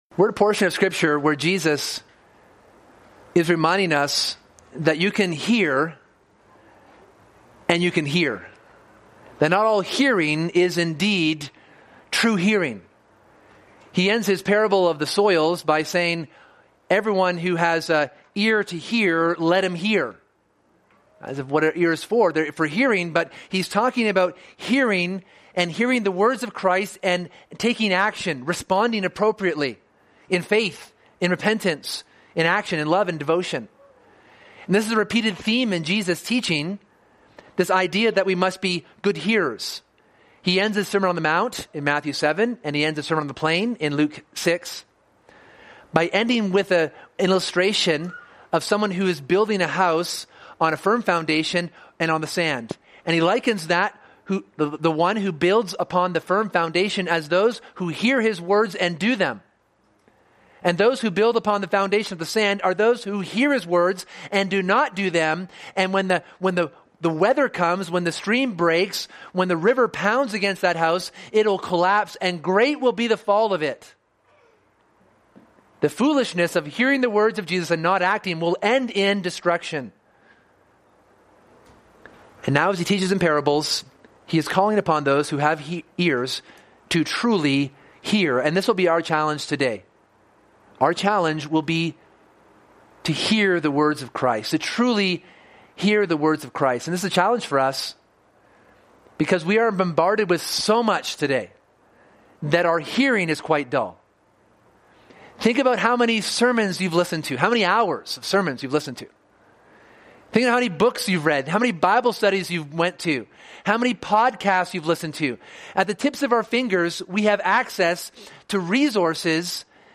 This sermon examines the parable of the lamp and is a call for us to hear and act upon the good news of the King and his kingdom.